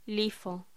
Locución: LIFO
voz